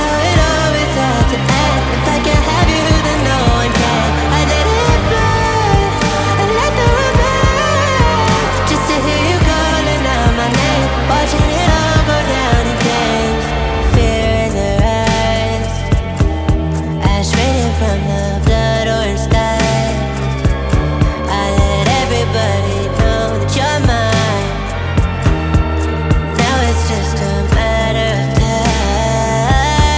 # Contemporary R&B